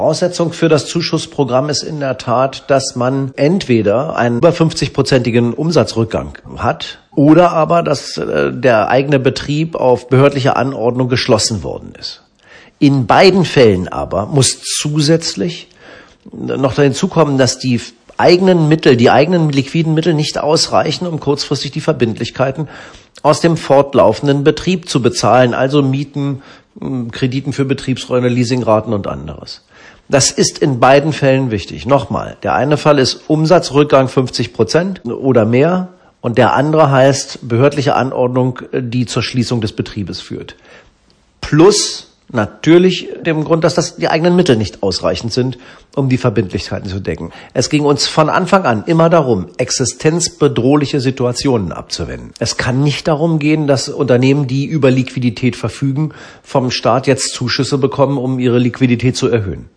Dazu ein paar Einordnungen und Erläuterungen von Wirtschaftsminister Dr. Bernd Buchholz (Audio starten – „im Browser anhören“)
Zu den Bedingungen des Zuschuss-Programms erläutert Buchholz – Audio starten